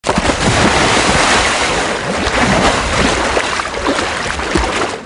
На этой странице собраны натуральные звуки шагов по лужам: от легкого шлепанья до энергичного хлюпанья.
Шум плюхнувшегося в глубокую лужу